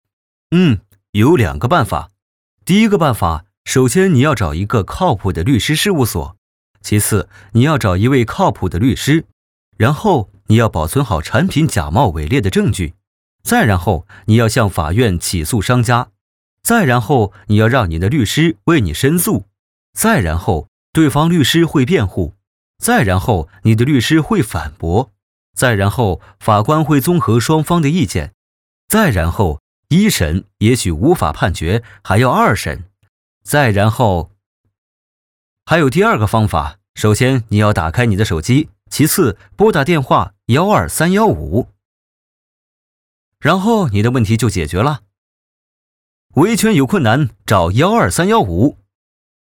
12315广播广告